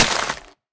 Minecraft Version Minecraft Version snapshot Latest Release | Latest Snapshot snapshot / assets / minecraft / sounds / block / muddy_mangrove_roots / break3.ogg Compare With Compare With Latest Release | Latest Snapshot
break3.ogg